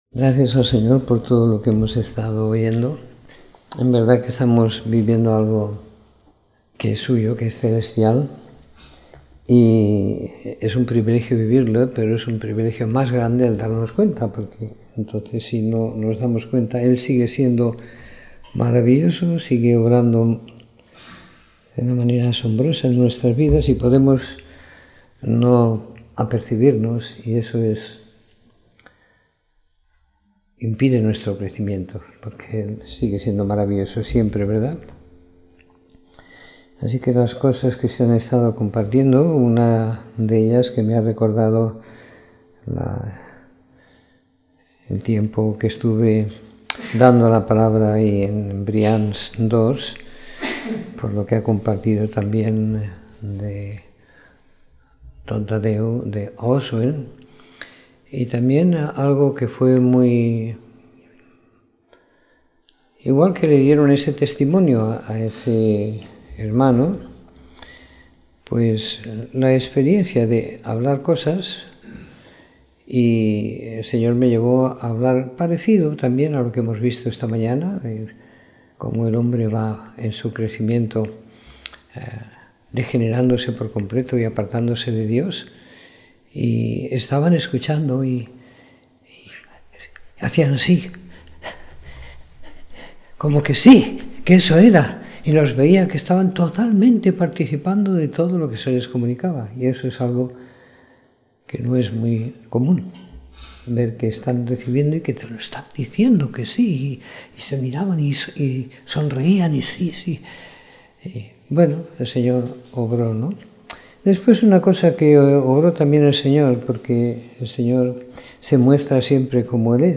Domingo por la Tarde . 29 de Enero de 2017
Escuchar la Reunión / Descargar Reunión en audio El hombre está en oscuridad. Esa misma condición suya, le hace fácil presa de los eruditos, estudiosos y excelentes comunicadores de la oscuridad.